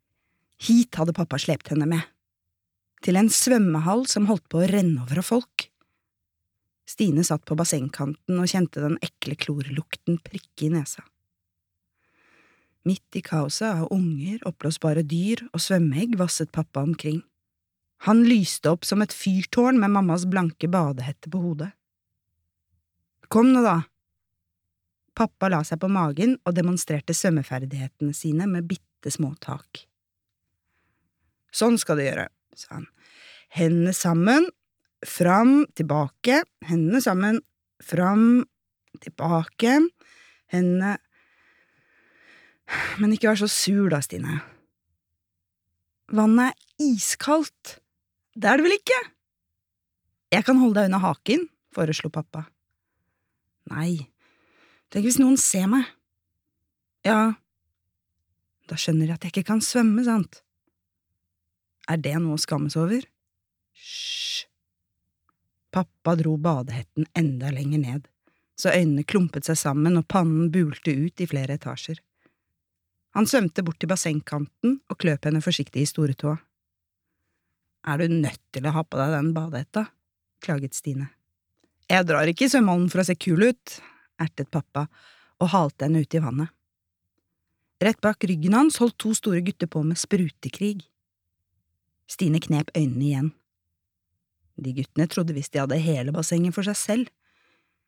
Stine kan ikke svømme (lydbok) av Tania Kjeldset